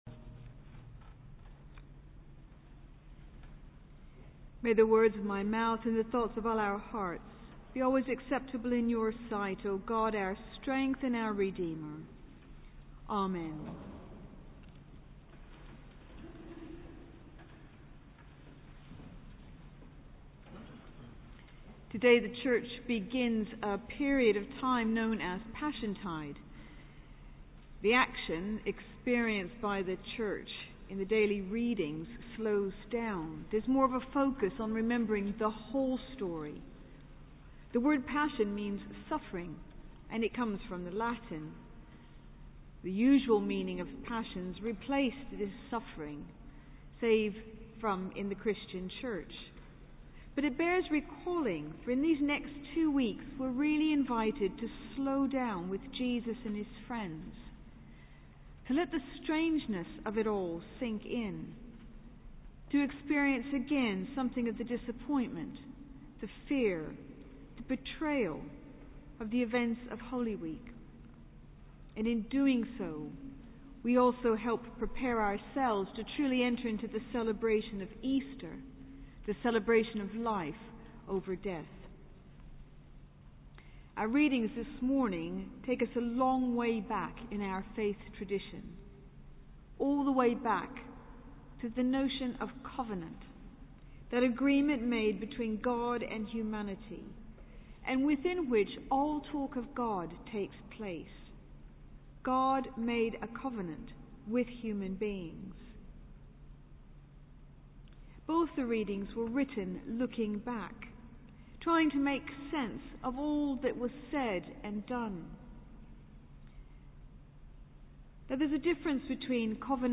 Sermon: Choral Evensong - 22 March 2015